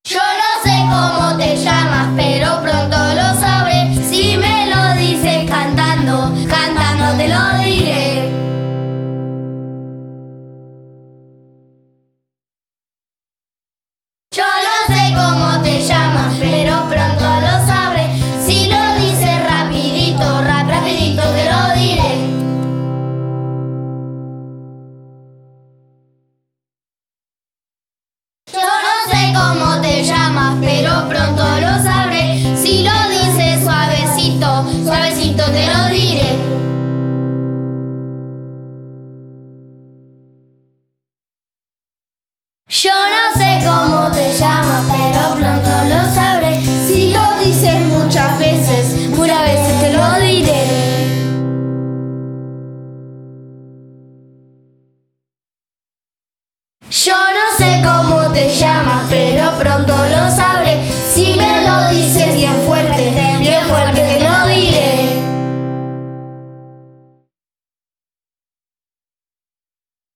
Pueden escuchar también una versión sin respuestas.